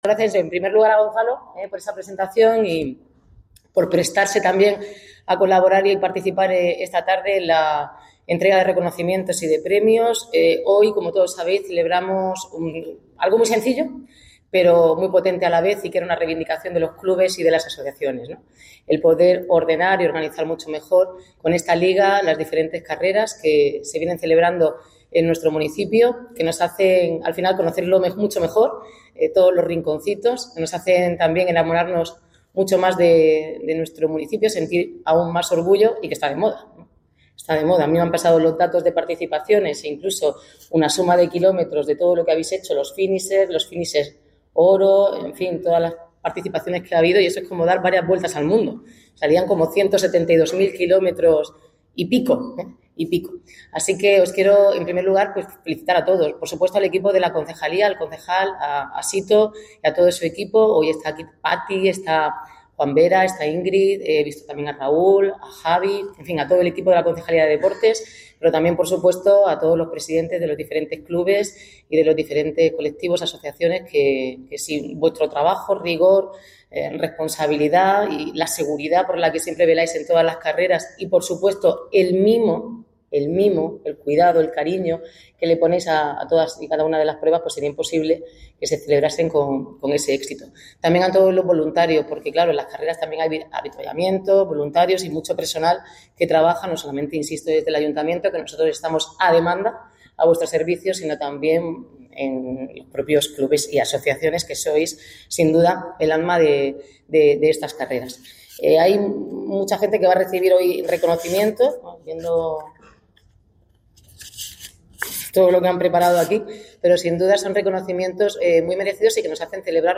La alcaldesa Noelia Arroyo preside la entrega de distinciones de esta primera edición que ha logrado superar las 15.000 participaciones en un calendario de 15 pruebas por todo el municipio
El evento, celebrado en el Centro Cultural Ramón Alonso Luzzy, ha estado presidido por la alcaldesa, Noelia Arroyo, junto a miembros del equipo de Gobierno y de la Corporación Local, así como representantes de colectivos deportivos y entidades sociales del municipio.